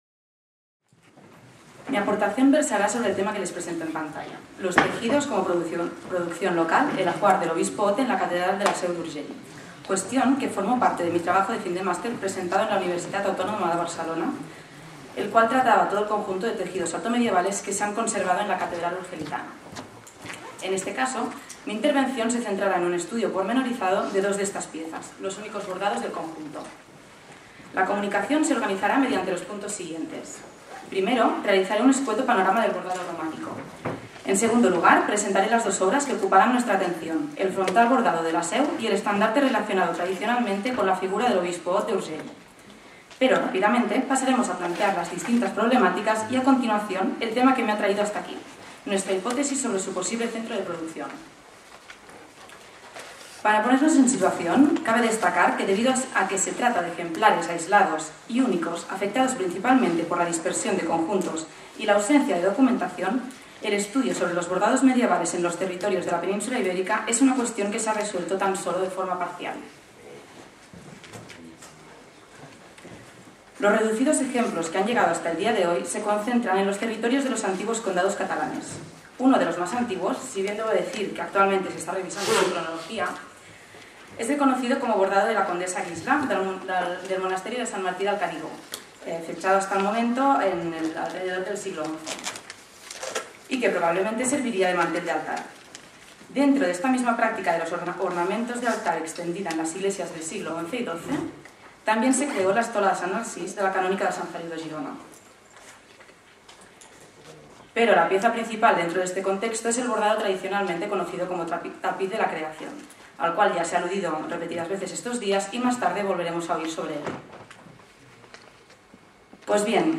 sobre dos teixits de l'alta edat mitjana de la catedral de la Seu d' Urgell, en el marc del Congrés Internacional 'Les catedrals catalanes en el context europeu (s. X-XII) : escenaris i escenografies', celebrat a Girona i a Vic els dies 7,8,9 i 10 de novembre de 2012